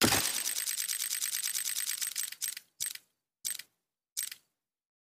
horizontal-roulette-open.mp3